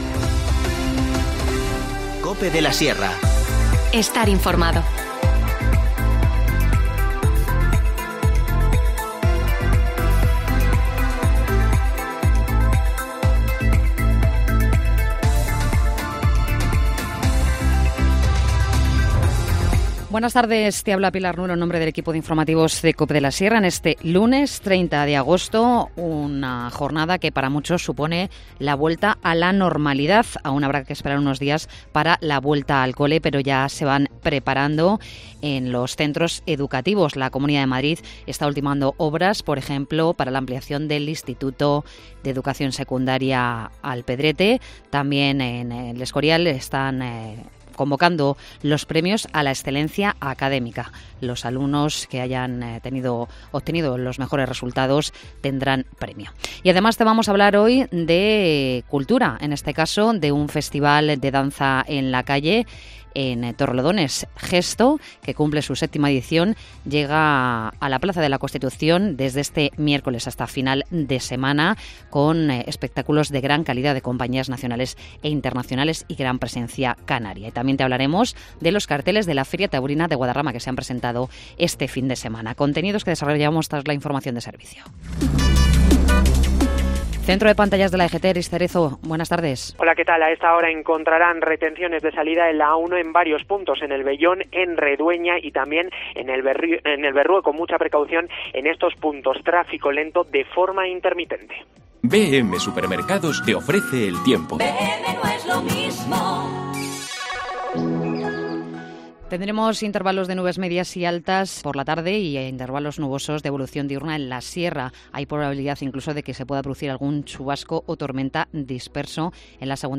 Informativo Mediodía 30 agosto